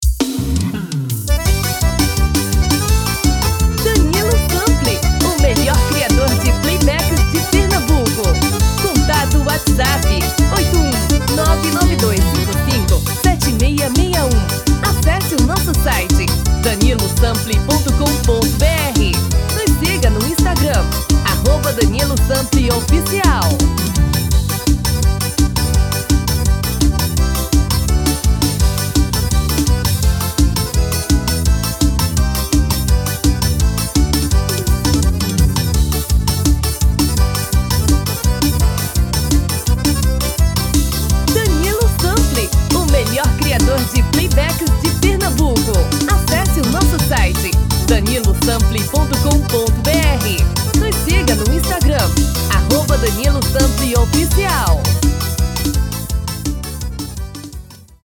DEMO 1: tom original / DEMO 2: tom masculino